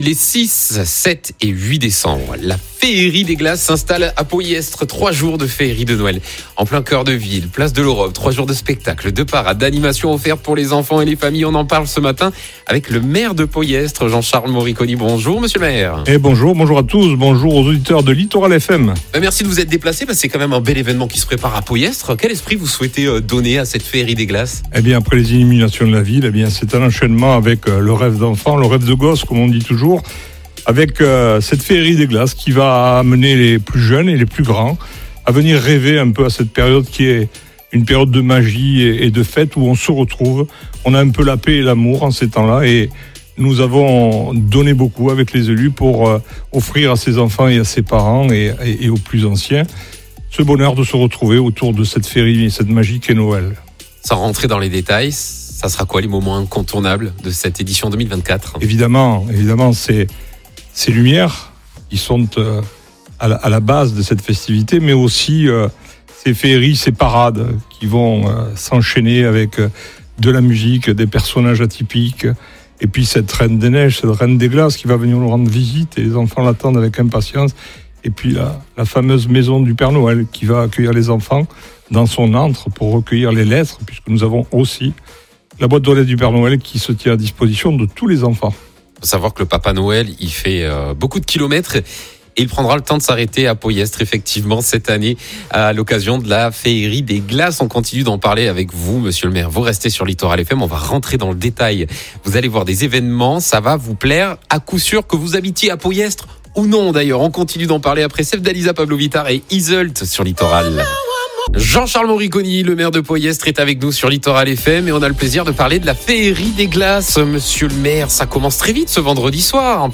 Un entretien à ne pas manquer, où le maire partage anecdotes, coulisses et son moment préféré de cette fête magique ❄